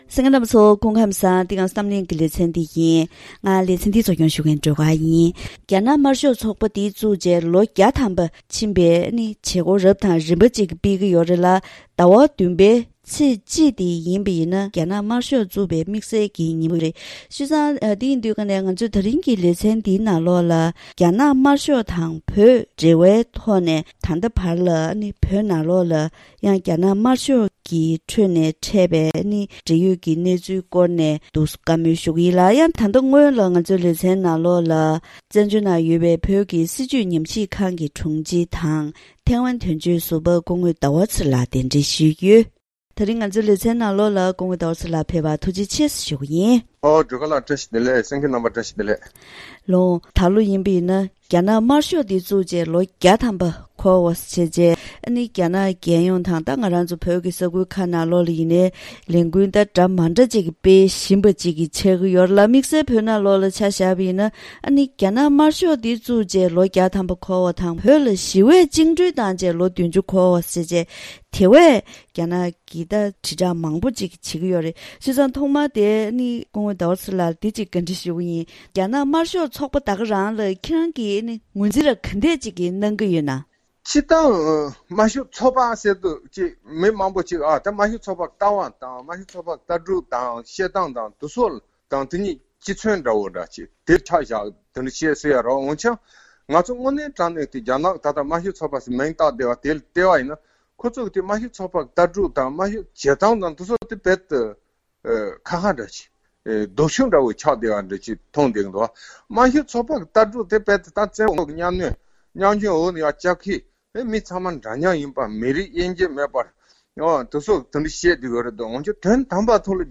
ད་རིང་གི་གཏམ་གླེང་ཞལ་པར་ལེ་ཚན་ནང་རྒྱ་ནག་དམར་ཤོག་ཚོགས་པ་བཙུགས་ནས་ལོ་ངོ་བརྒྱ་འཁོར་བའི་སྐབས་དེར་བོད་ནང་དམར་ཤོག་གི་དྲིལ་བསྒྲགས་དང་སློབ་གསོ་རིམ་པ་སྤེལ་བཞིན་ཡོད་པ་དང་། དམར་ཤོག་ཚོགས་པའི་ལྟ་བར་བོད་པས་ངོས་འཛིན་དང་རྒྱལ་སྤྱིའི་སྤྱི་ཚོགས་ནས་ད་ལྟ་དུས་ཚོད་འདིར་རྒྱ་ནག་དམར་ཤོག་ཚོགས་པར་ངོས་འཛིན་ཇི་ཡོད་སོགས་ཀྱི་སྐོར་ལ་གནད་དོན་དབྱེ་ཞིབ་པ་དང་ལྷན་དུ་བཀའ་མོལ་ཞུས་པ་ཞིག་གསན་རོགས་གནང་།